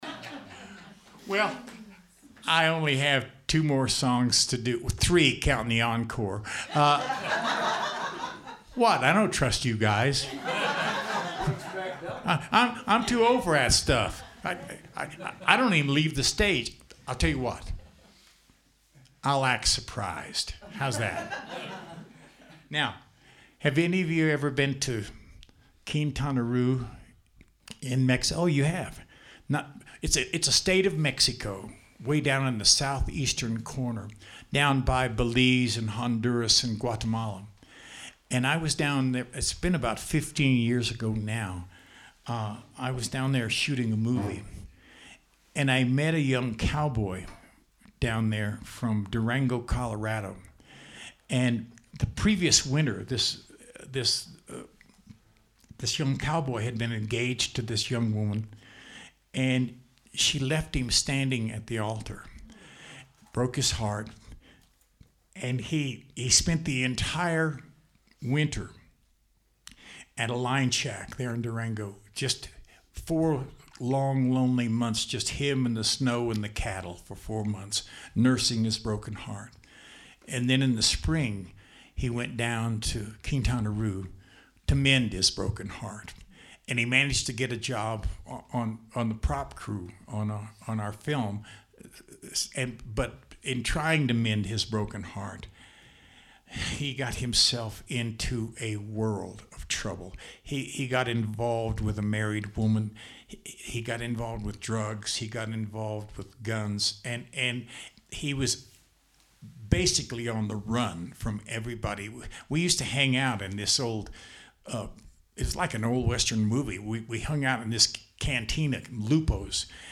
As most of you know, I’m a storyteller and there is a tale that goes with every song.
Quintanaroo-Story.mp3